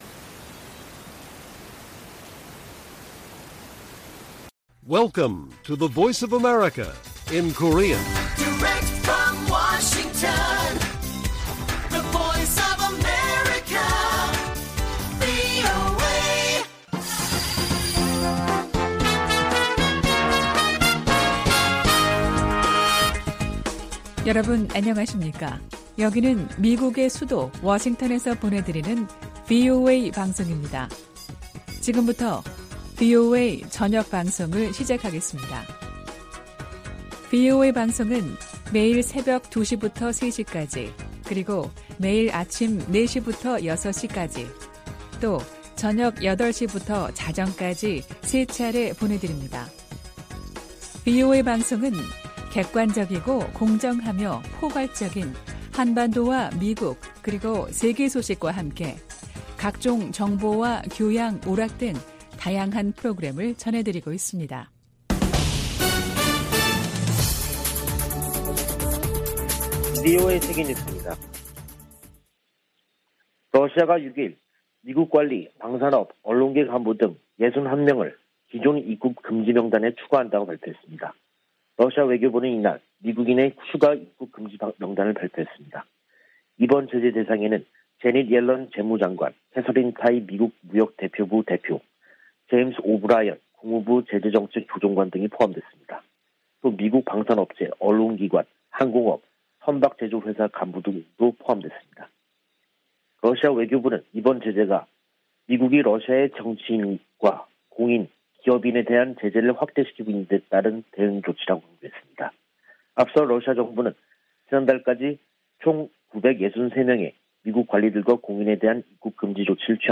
VOA 한국어 간판 뉴스 프로그램 '뉴스 투데이', 2022년 6월 7일 1부 방송입니다. 북한이 7차 핵실험을 감행할 경우 미국과 한국은 신속하고 강력한 대응을 할 것이라고 서울을 방문중인 웬디 셔먼 미국 국무부 부장관이 경고했습니다. 국제원자력기구는 북한 풍계리에서 핵실험을 준비 징후를 포착했다고 밝혔습니다. 미국의 전문가들은 북한이 최근 8발의 단거리탄도미사일을 발사한 것은 전시 한국에 기습 역량을 과시하려는 것이라고 분석했습니다.